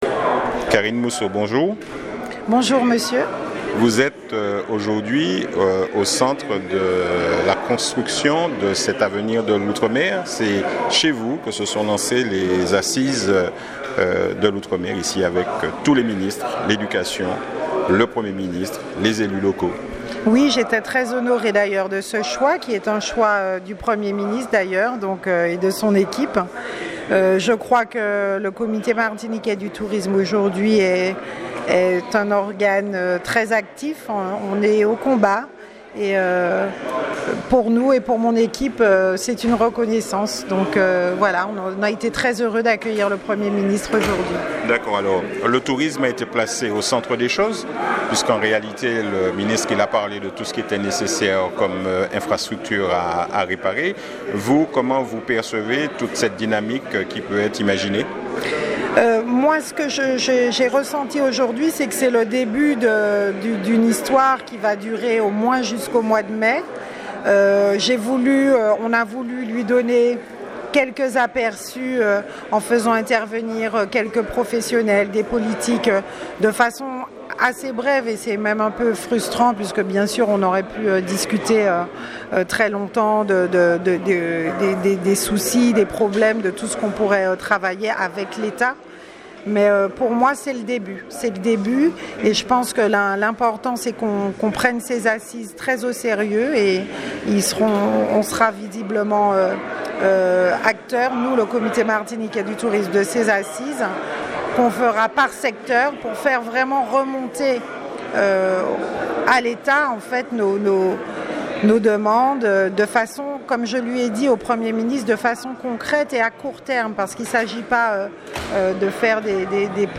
Et c'est chez elle au CMT dont elle est la présidente que les assises des outremers débutèrent.
Elle a bien voulu répondre à nos questions, non sans nous avoir dit ce qu'elle pensait de notre façon de traiter l'information.